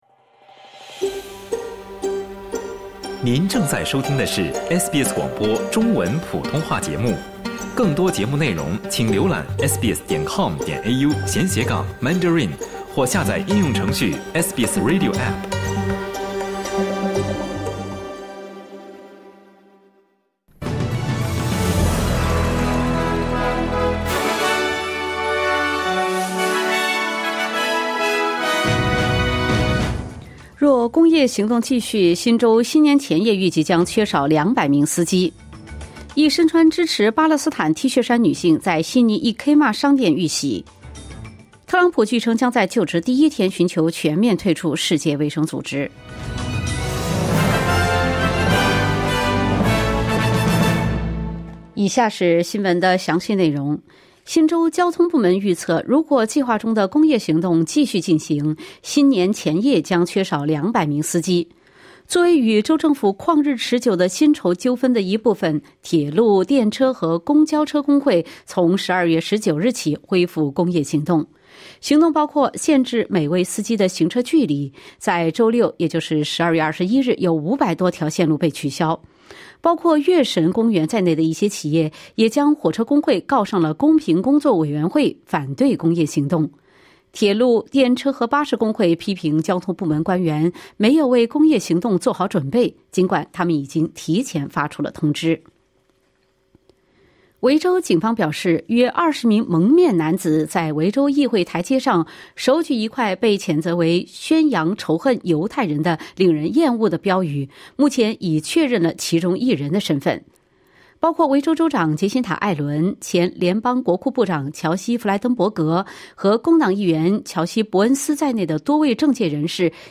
SBS早新闻（2024年12月23日）